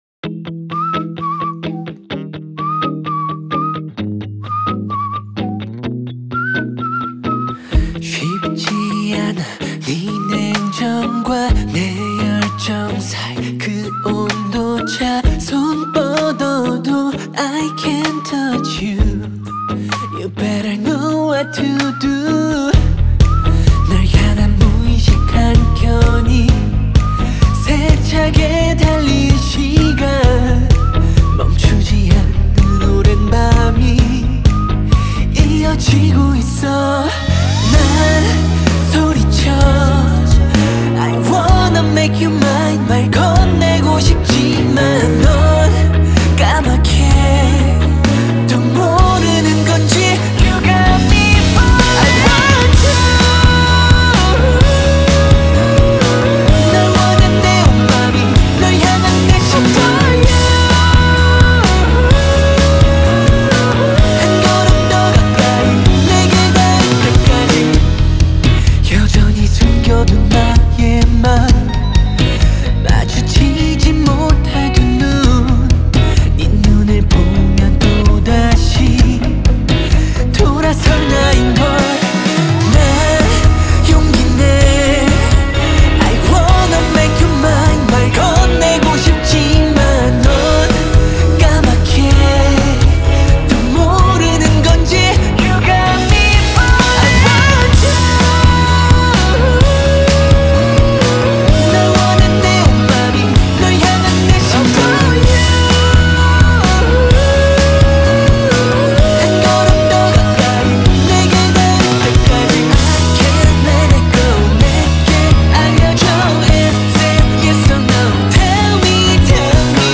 کیپاپ